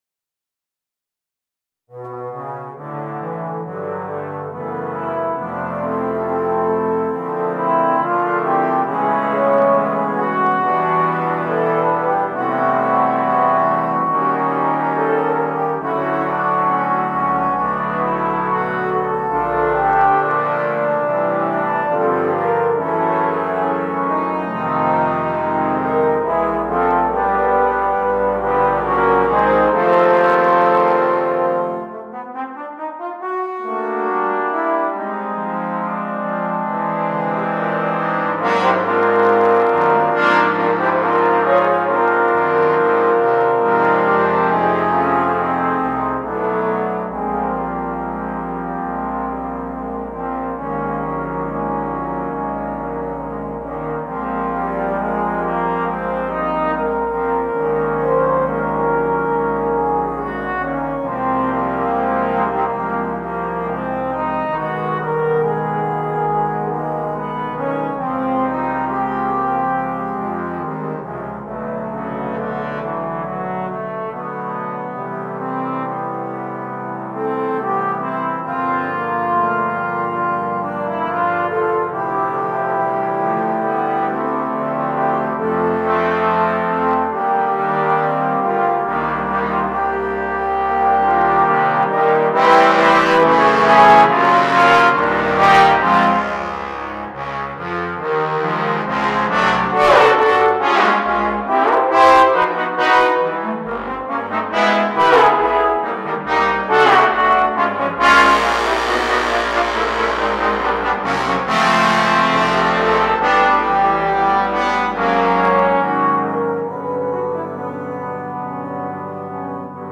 8 Trombones